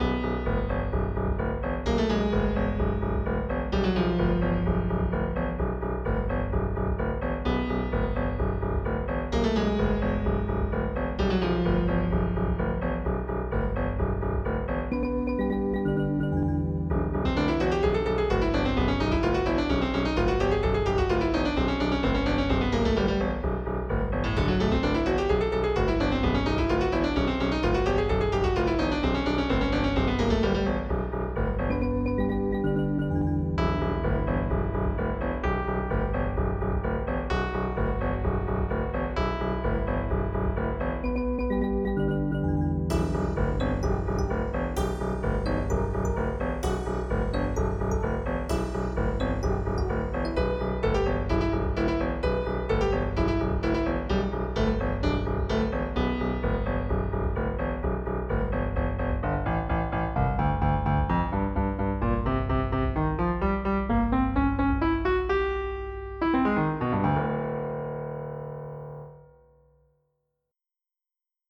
2 channels